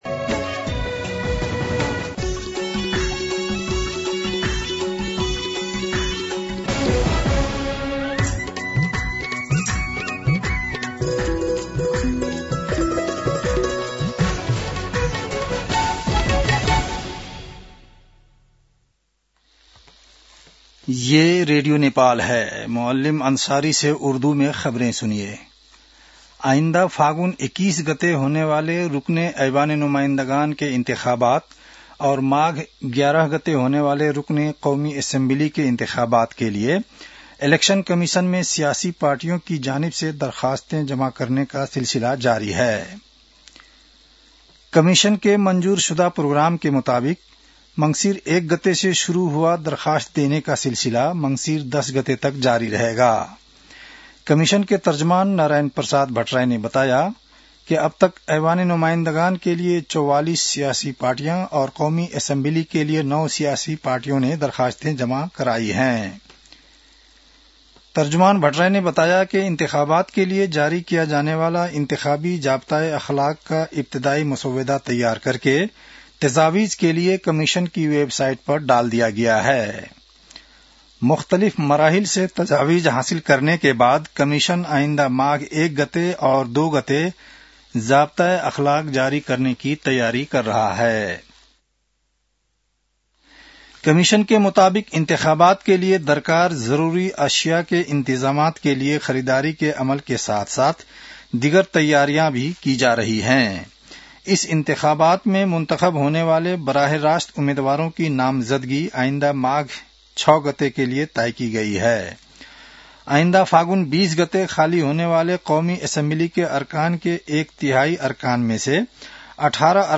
उर्दु भाषामा समाचार : ७ मंसिर , २०८२